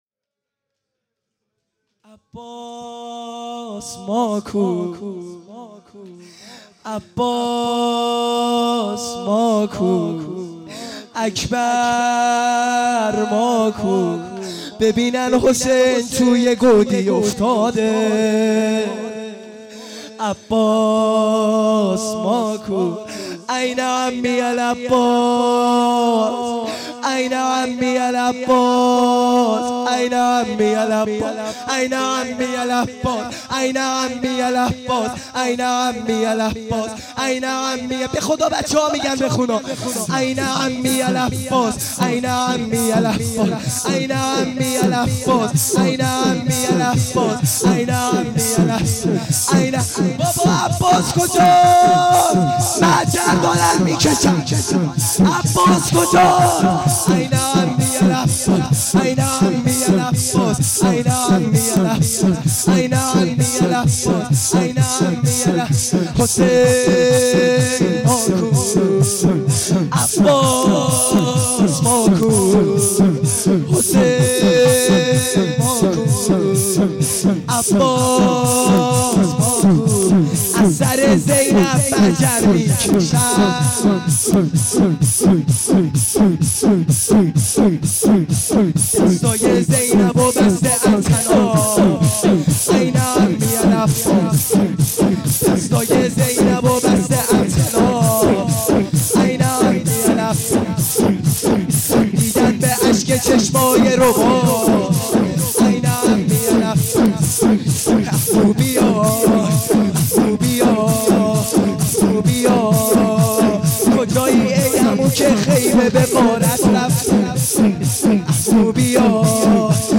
خیمه گاه - بیرق معظم محبین حضرت صاحب الزمان(عج) - لطمه زنی | بخش دوم